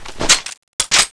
reload.wav